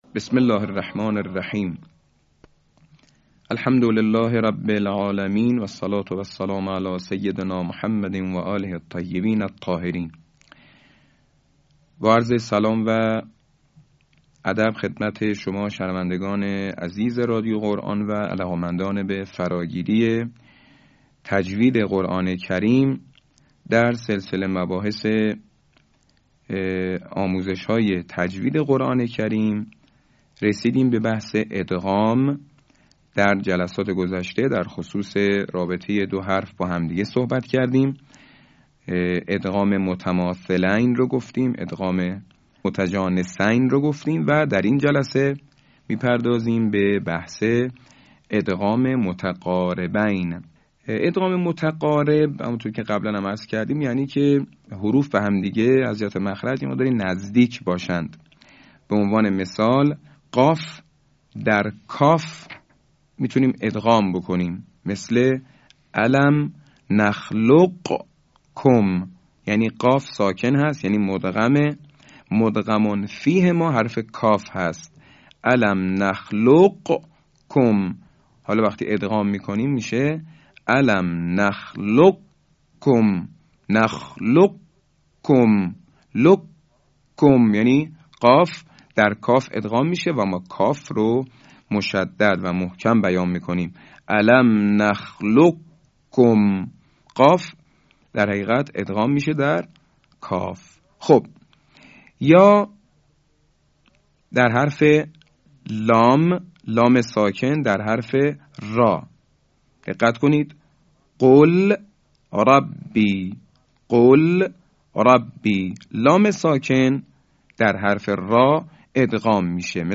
صوت | آموزش ادغام متقاربین